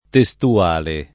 [ te S tu- # le ]